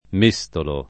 m%Stolo] s. m. — es. con acc. scr.: méstoli, cucchiai, matterelli per la pasta [